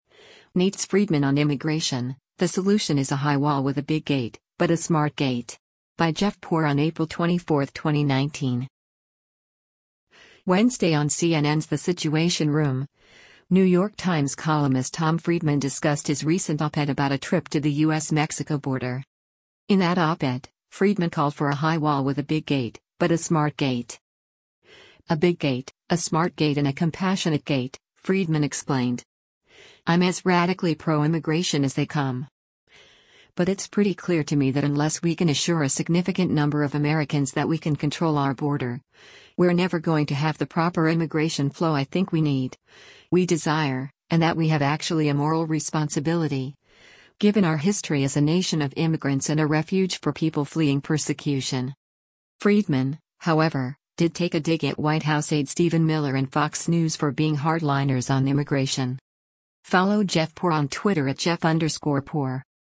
Wednesday on CNN’s “The Situation Room,” New York Times columnist Tom Friedman discussed his recent op-ed about a trip to the U.S.-Mexico border.